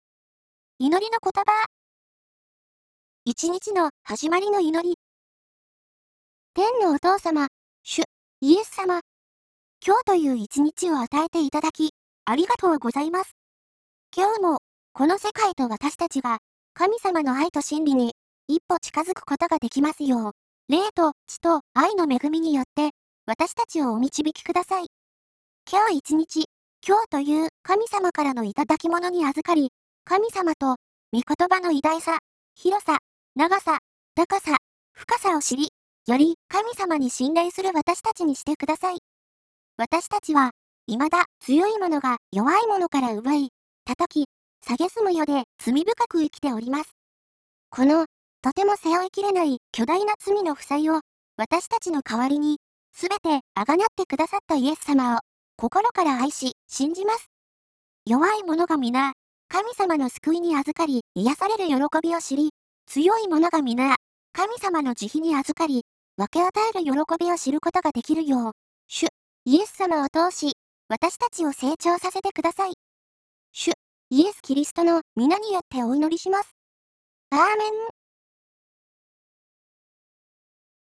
入力あるいはコピペしたテキストを読み上げてくれるソフトなのですが、 「東北ずん子」というキャラクター(声優)の合成音声によって読み上げてくれます。
東北ずん子読み上げ「一日の始まりの祈り(オリジナル)」ダウンロード
prayer02_original.wav